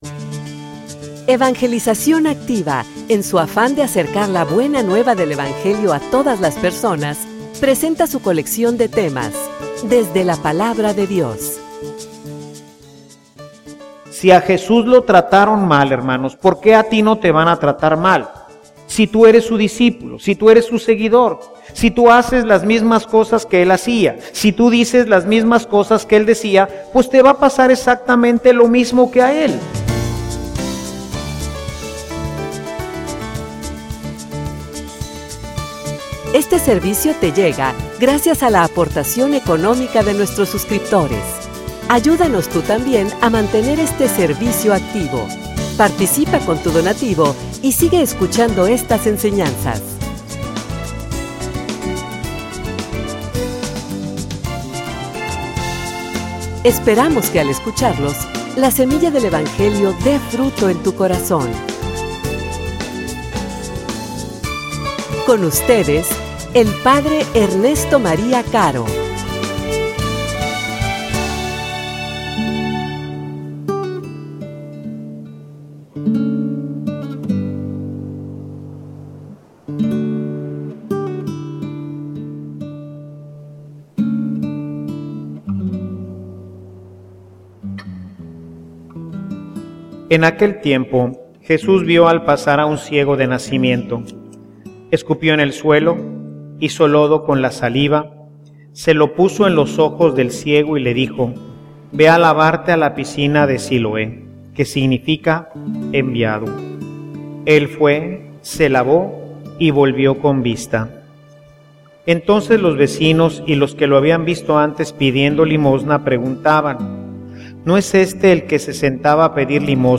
homilia_Vale_la_pena.mp3